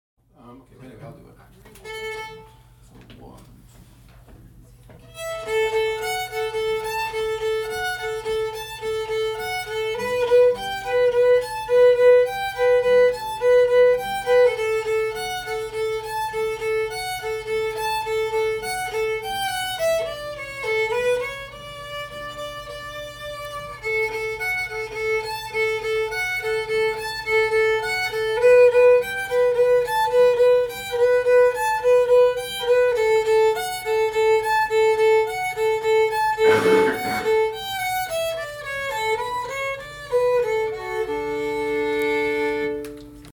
Liberty shuffle